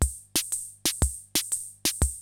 CR-68 LOOPS1 2.wav